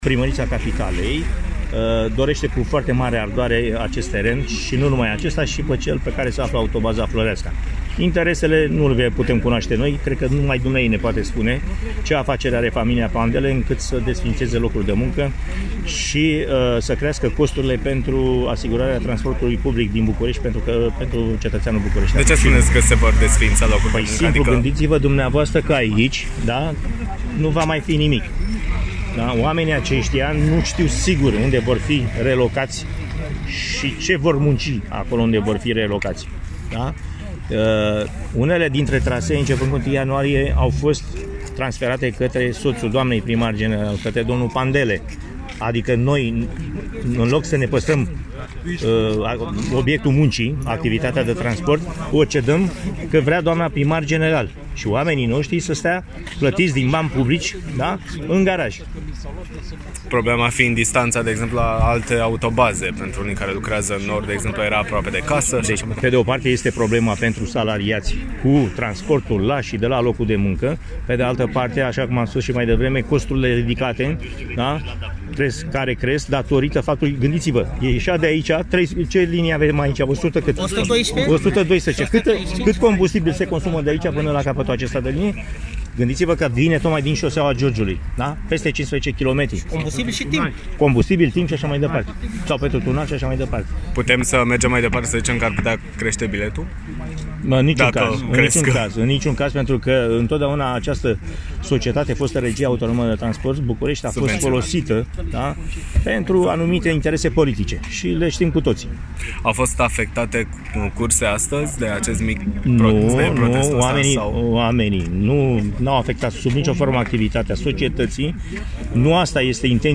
Câteva zeci de angajați ai Societății de Transport București (STB) protestează la Podul Pipera, nemulțumiți că Autobaza Pipera va fi relocată etapizat, în același loc urmând să fie construit Spitalul Metropolitan.